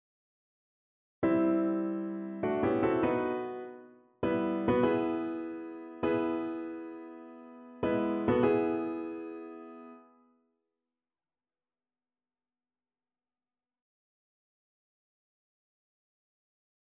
Horns:
(I had to change the audio to a piano, because it wasn’t possible for me to add any sharps or flats for the horns)